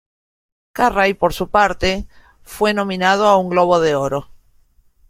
Pronounced as (IPA) /nomiˈnado/